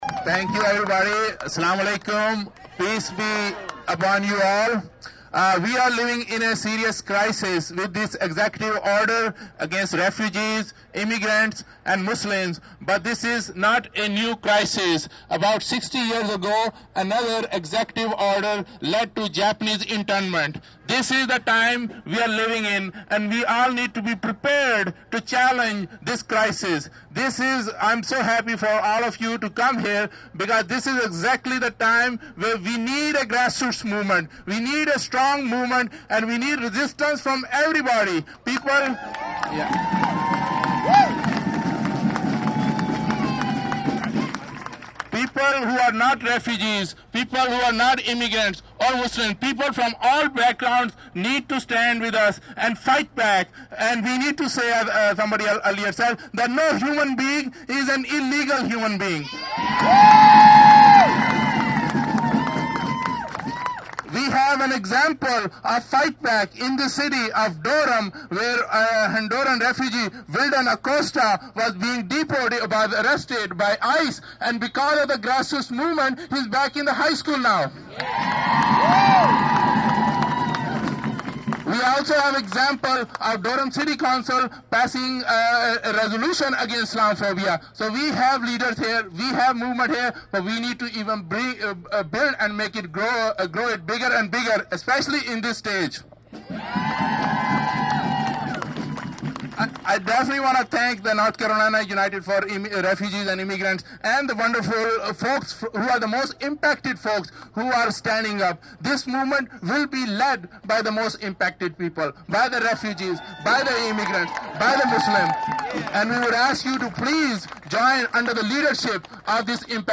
Demonstrations began locally on Friday morning, as about 150 people gathered in downtown Durham for a rally organized by Church World Service.